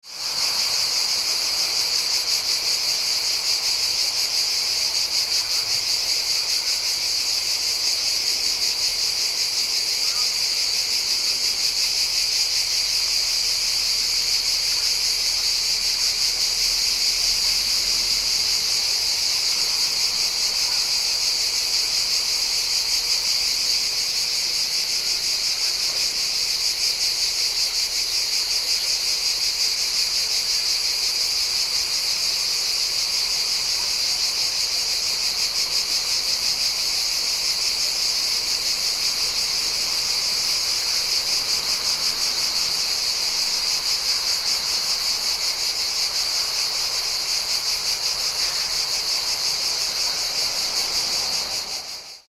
Summer Insect Sounds: A Mediterranean Experience
Loud chorus of cicadas chirping in the Mediterranean nature of Greece. A typical summer ambiance filled with relaxing natural sounds.
Genres: Sound Effects
Summer-insect-sounds.mp3